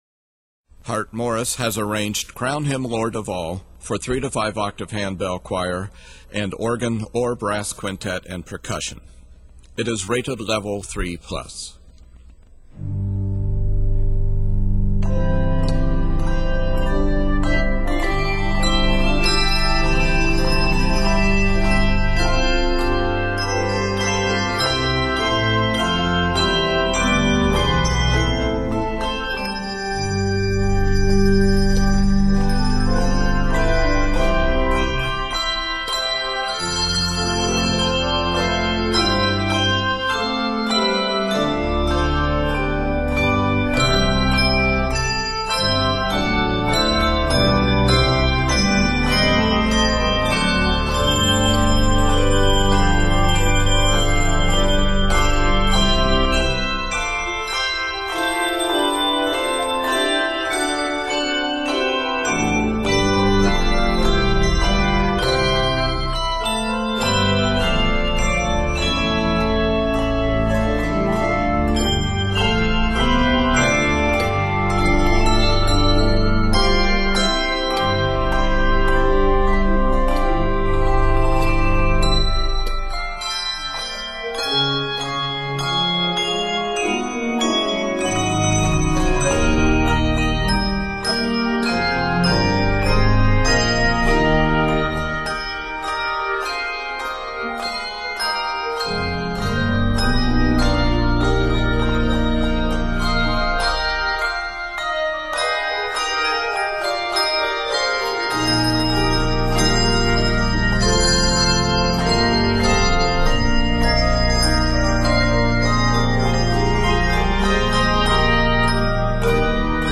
the textures are fully scored, keeping all ringers involved.